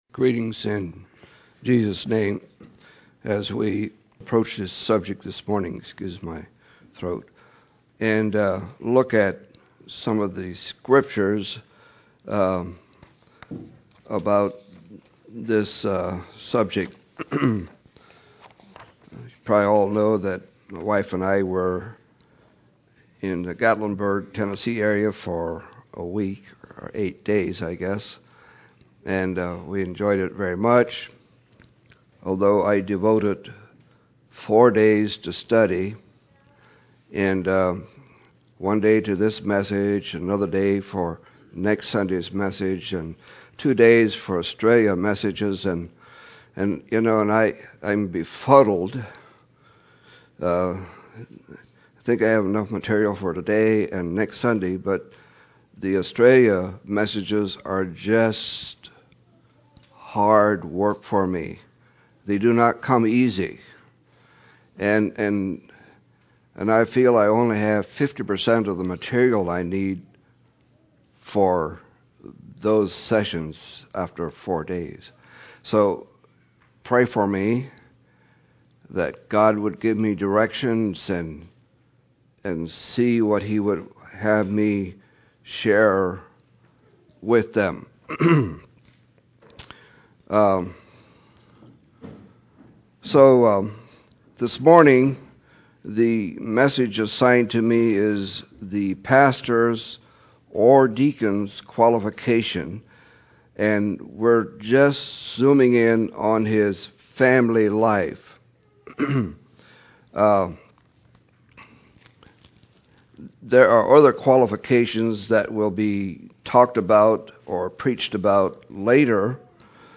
July 26, 2015 – Crosspointe Mennonite Church